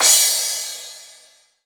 Crash Turntable 1.wav